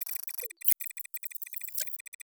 Digital Bling Alert 1.wav